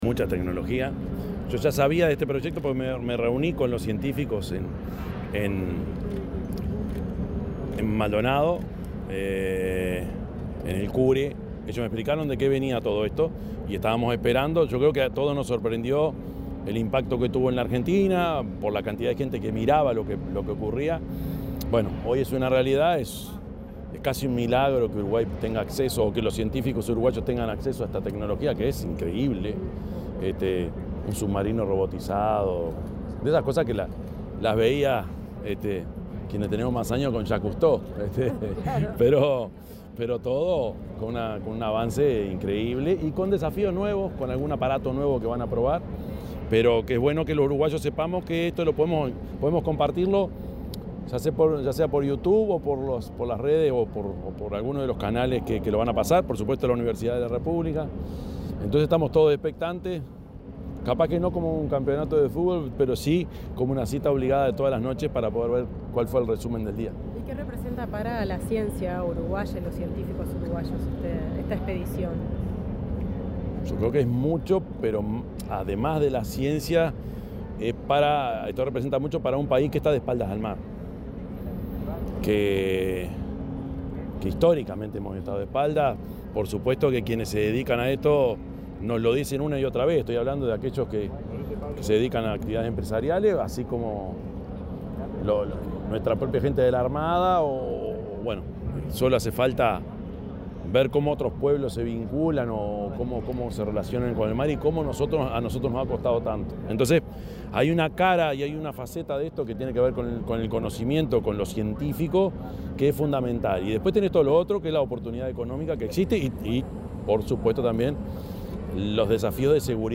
Declaraciones del presidente Yamandú Orsi
El presidente de la República, Yamandú Orsi, dialogó con los medios de prensa, tras recorrer la embarcación de la campaña científica Uruguay Sub-200.